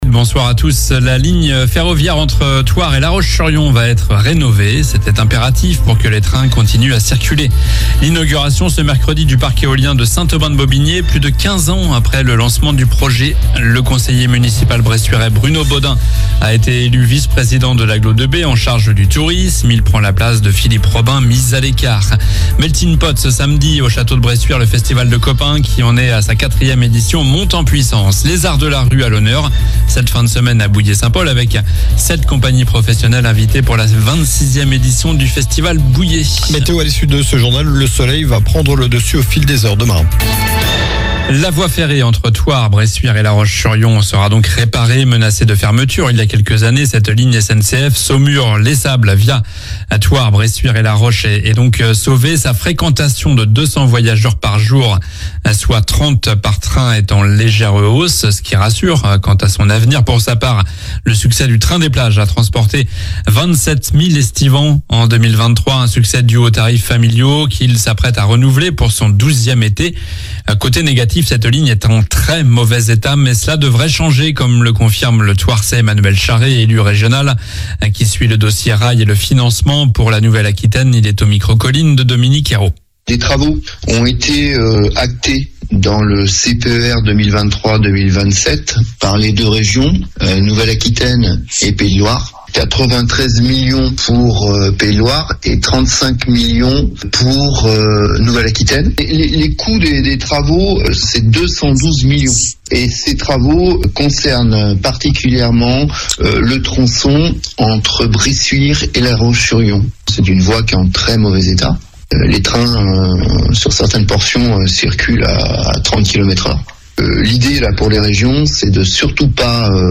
Journal du mercredi 03 juillet (soir)